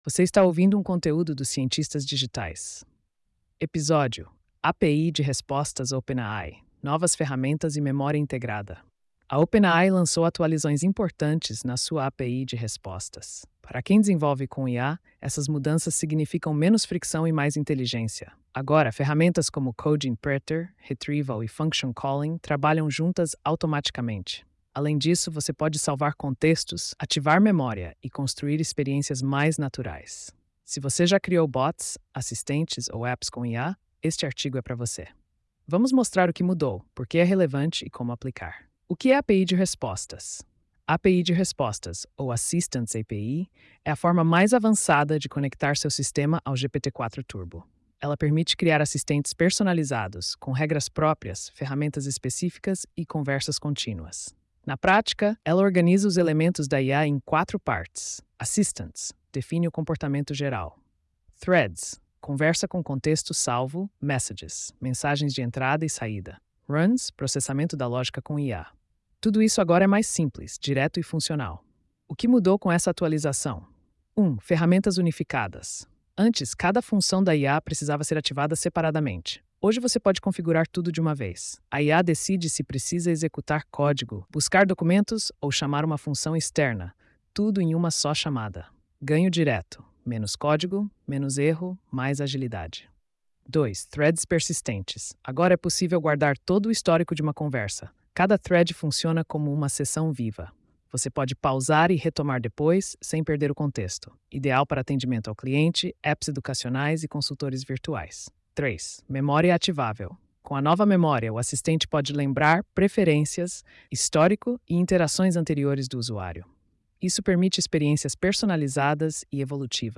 post-3169-tts.mp3